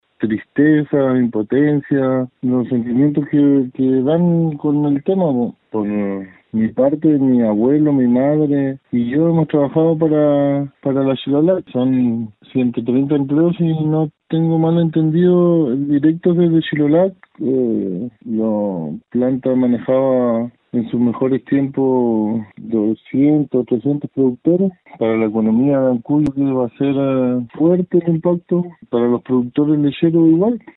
productor-lechero.mp3